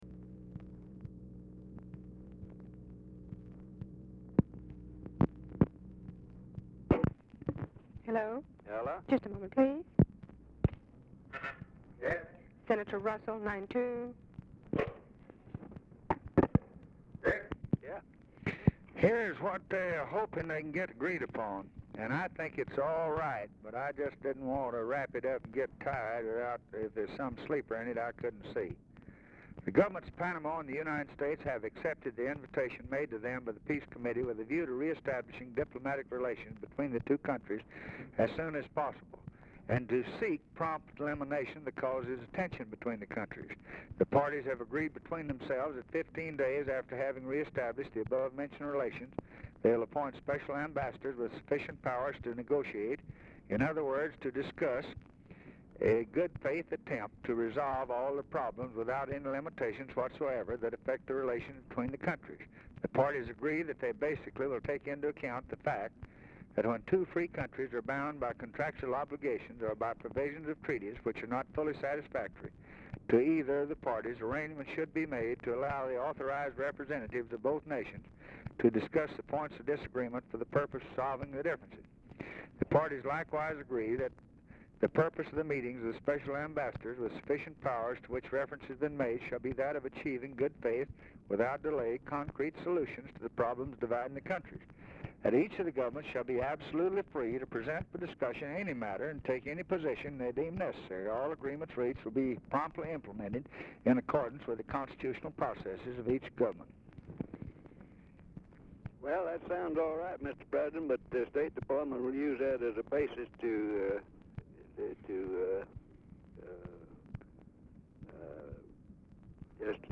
Telephone conversation # 1545, sound recording, LBJ and RICHARD RUSSELL, 1/25/1964, 2:30PM
Format Dictation belt
Location Of Speaker 1 Oval Office or unknown location